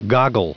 Prononciation du mot goggle en anglais (fichier audio)
Prononciation du mot : goggle